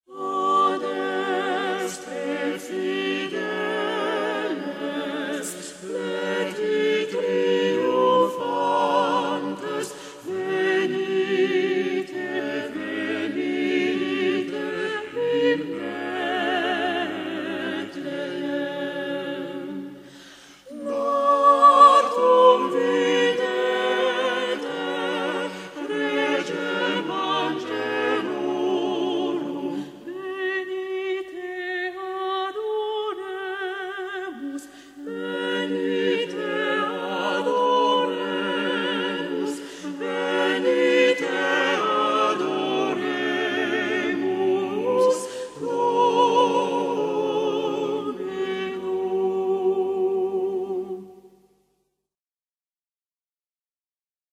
Les plus beaux chants de Noël servis par de grandes voix d’opéra !
Récital A Capella tout public
par le Manège Lyrique